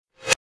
جلوه های صوتی
برچسب: دانلود آهنگ های افکت صوتی اشیاء دانلود آلبوم صدای کلیک موس از افکت صوتی اشیاء